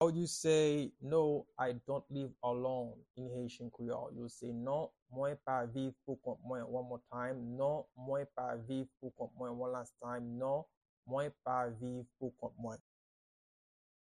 Pronunciation and Transcript:
No-I-dont-live-alone-in-Haitian-Creole-Non-mwen-pa-viv-pou-kont-mwen-pronunciation-by-a-Haitian-teacher.mp3